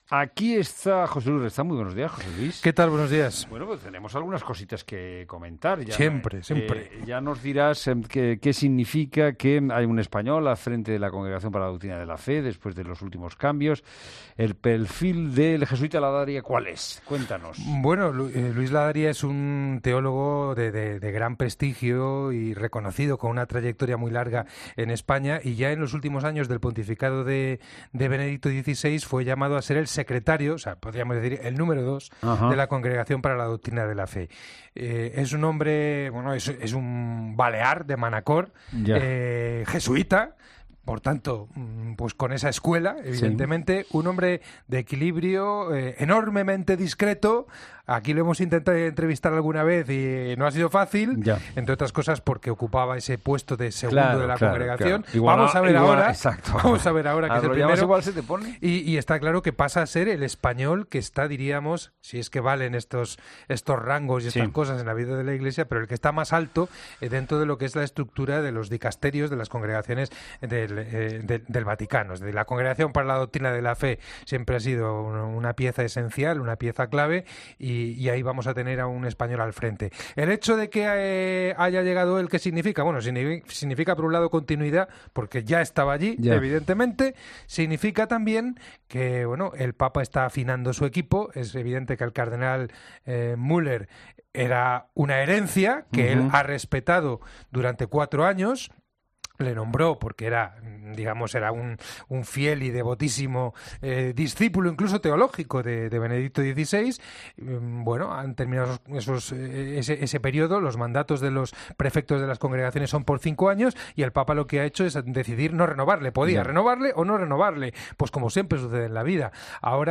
Las noticias de la Iglesia